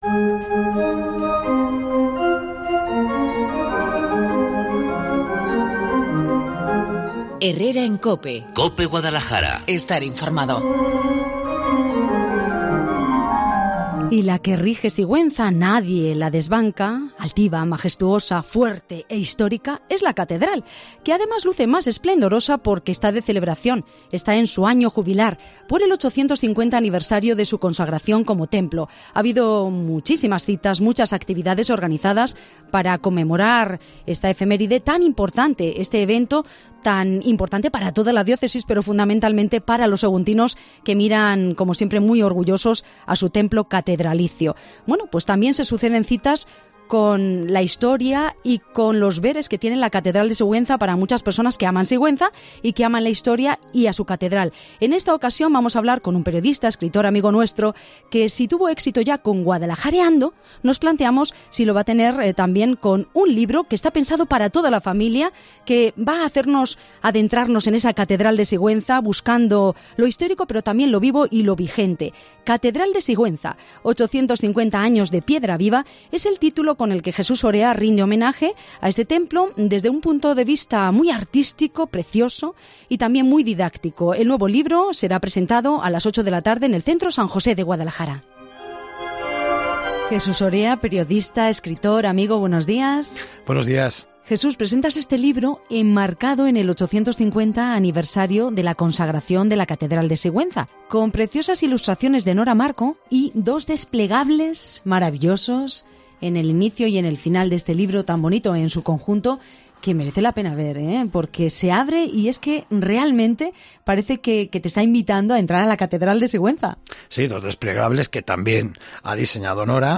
En Cope Guadalajara, hemos charlado con